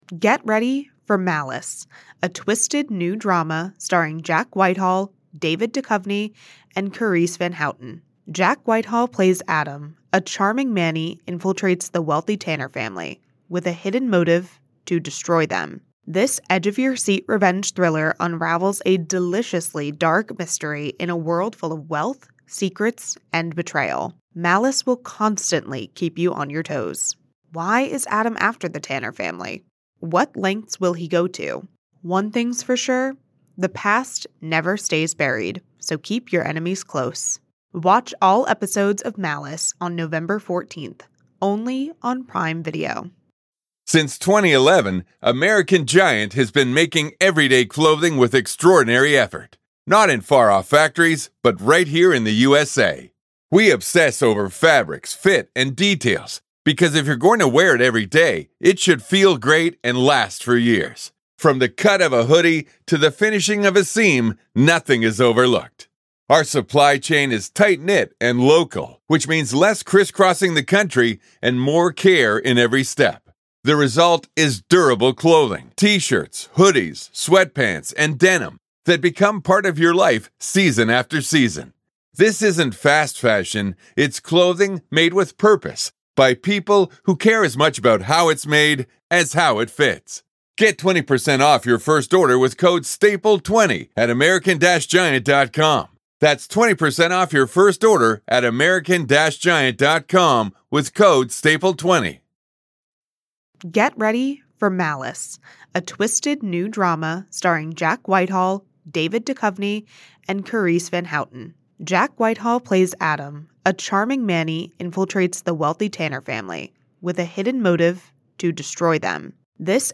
In this exclusive conversation